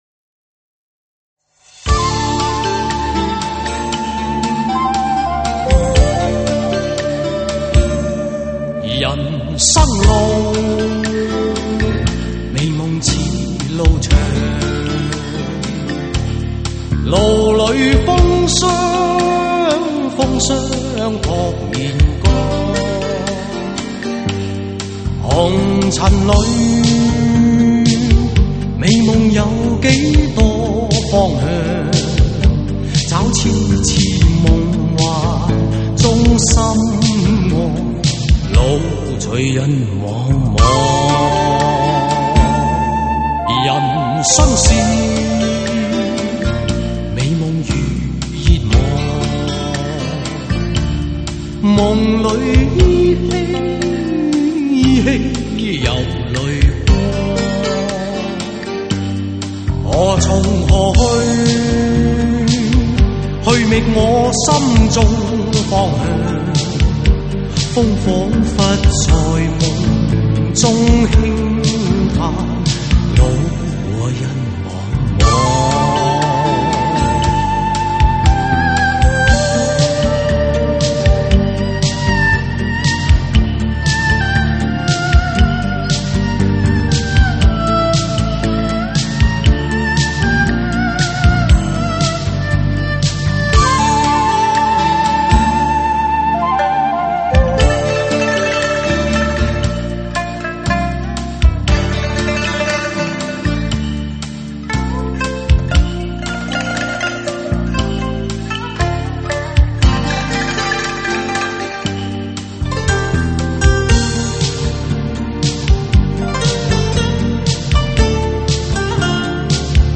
在普通CD机上亦能取得LP黑胶唱片般的 音乐享受